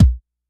SBV_V12_Kick_001.wav